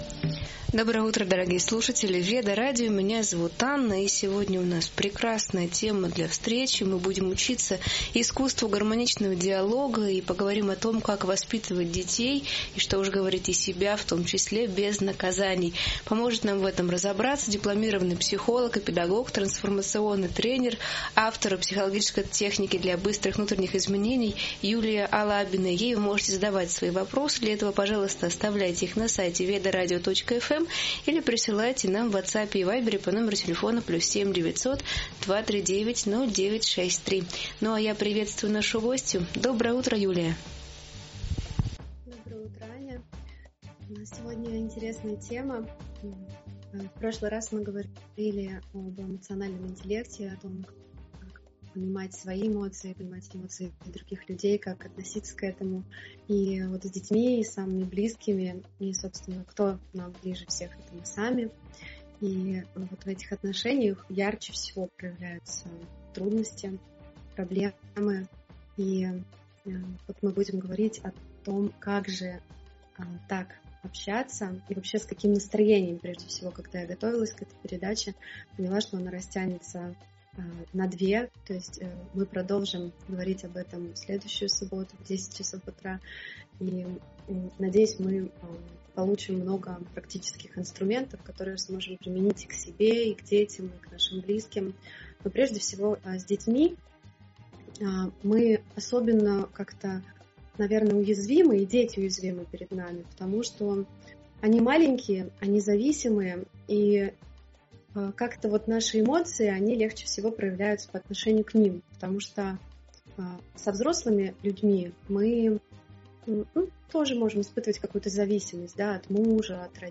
В эфире обсуждается важность воспитания детей без страха и наказаний, подчеркивается влияние эмоционального интеллекта родителей на развитие детей. Психолог раскрывает причины плохого поведения, включая желание внимания и неуверенность в любви.